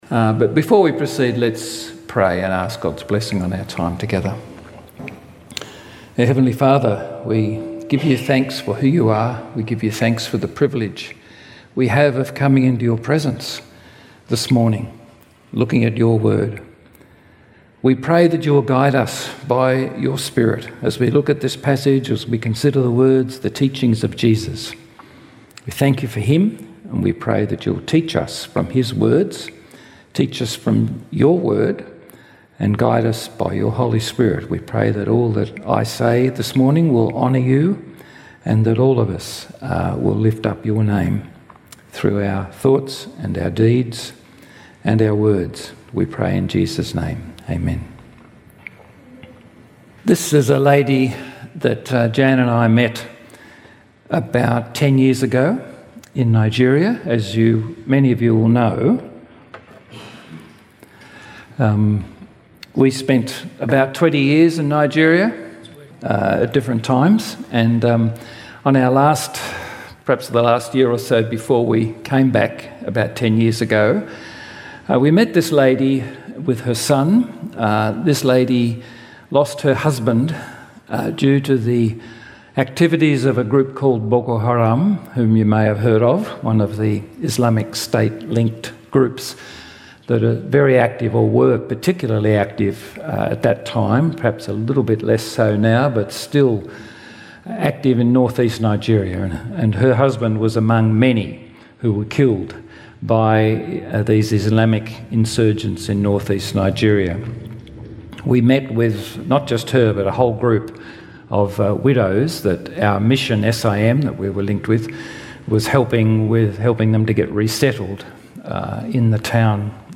Summerhill Baptist Church Sermons